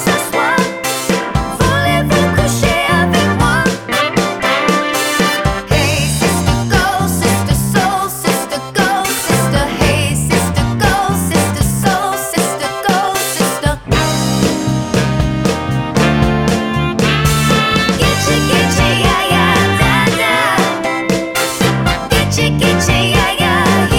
no Backing Vocals Disco 4:01 Buy £1.50